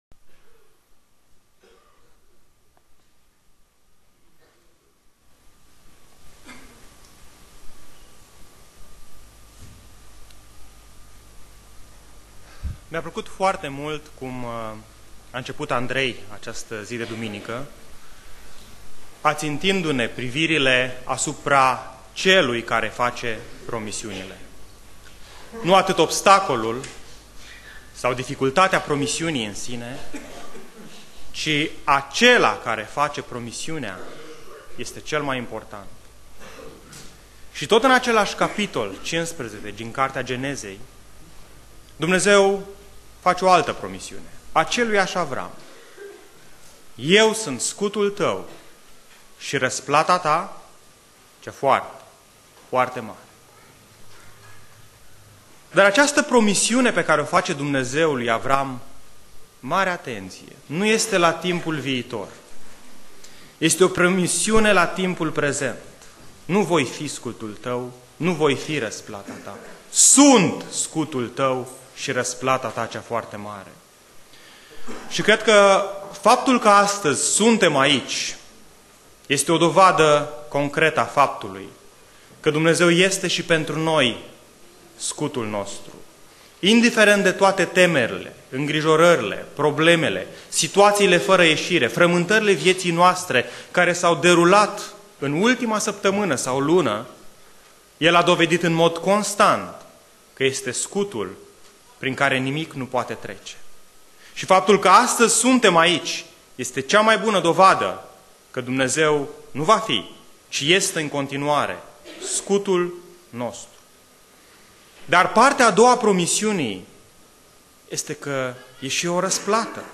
Predica Exegeza - Ieremia cap. 2